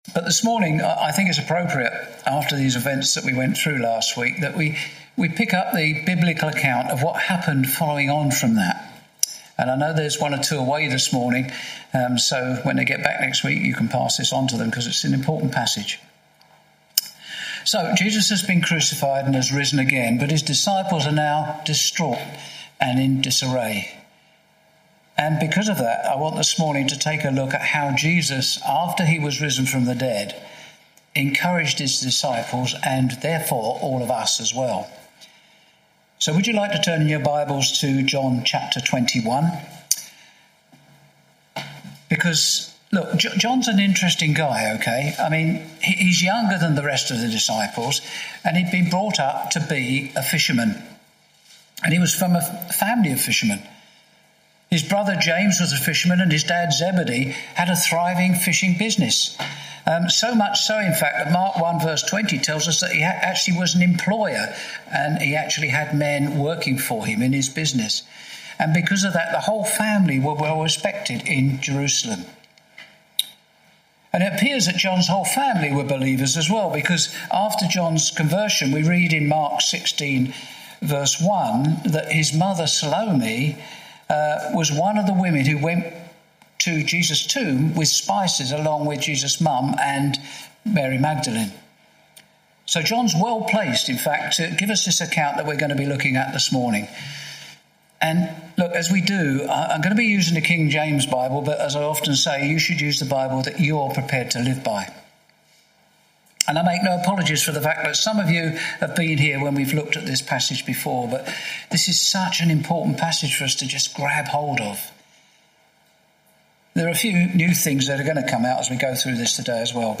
Share this: Share on X (Opens in new window) X Share on Facebook (Opens in new window) Facebook Share on WhatsApp (Opens in new window) WhatsApp Series: Sunday morning studies Tagged with Topical Bible studies